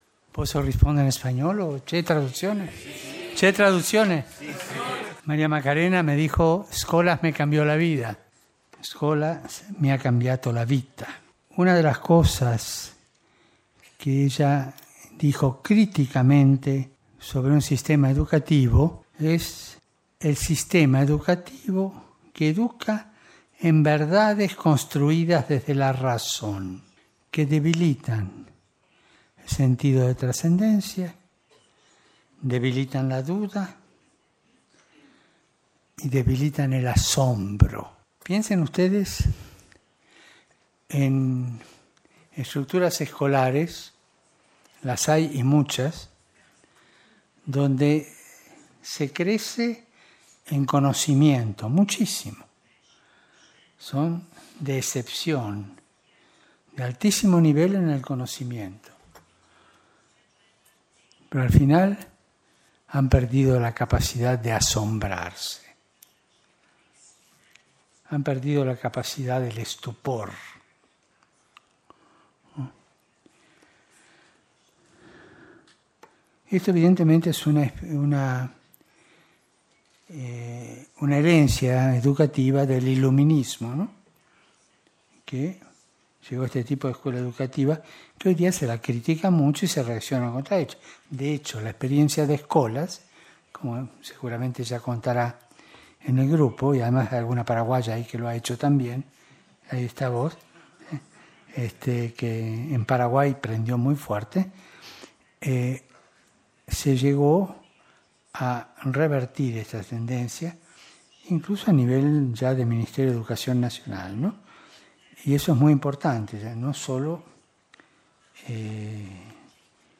El Santo Padre respondió a las preguntas formuladas por cinco jóvenes participantes en la reunión presinodal.